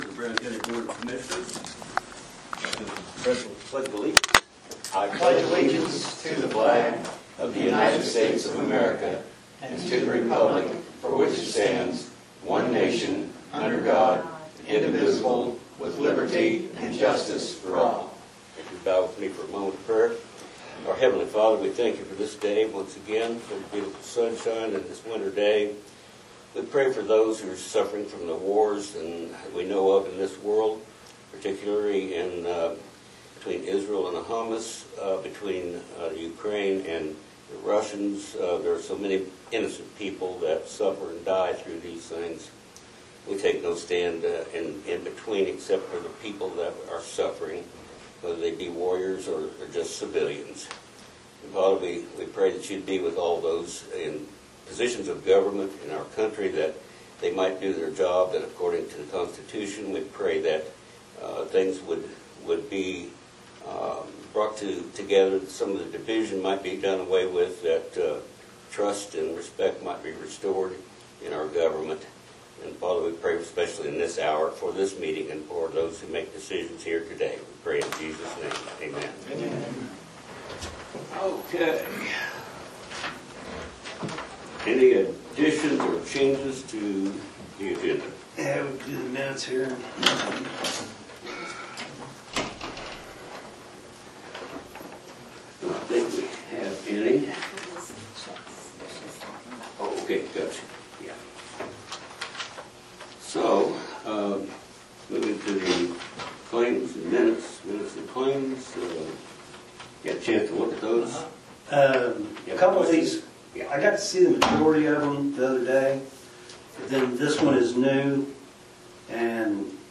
Commissioner Meeting Notes, Dec 15, 2023